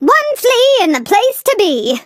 flea_lead_vo_04.ogg